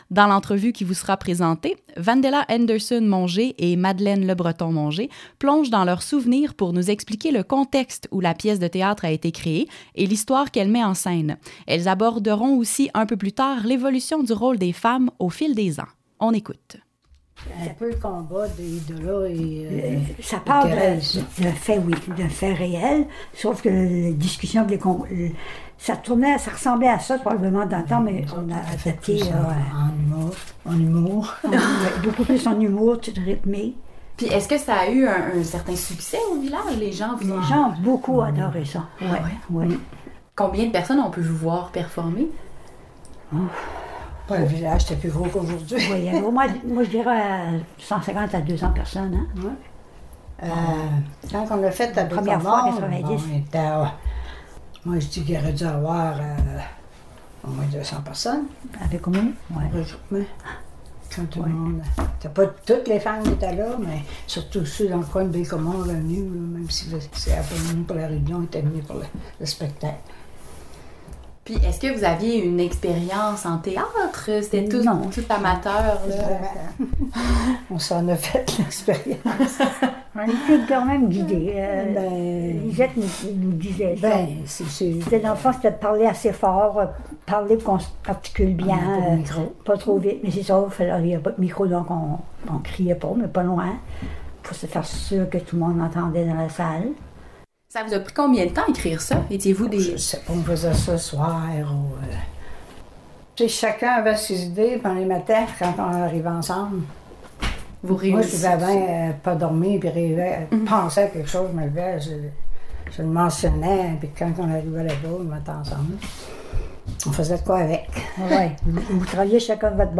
Les deux dames qui faisaient alors partie du regroupement des Cousines d’Adéline se remémorent avec nous l’époque où elles et d’autres femmes du village s’étaient regroupées pour présenter une pièce de théâtre portant sur les suffragettes, lesquelles se sont battues pour l’obtention du droit de vote au Québec.